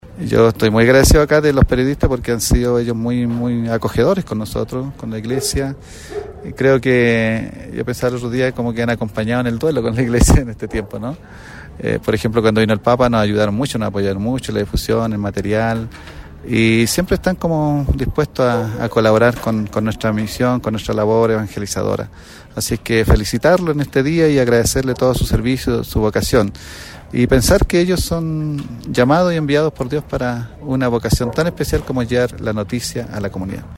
OBISPO-OSCAR-BLANCO-MARTINEZ-3-Agradecimiento-a-los-periodistas-de-Calama.mp3